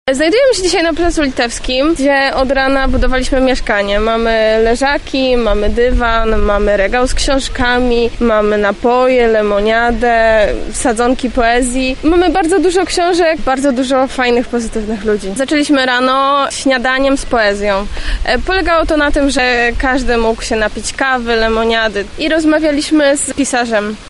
Odwiedziliśmy mieszkanie poezji, które do piątku będzie na Placu Litewskim.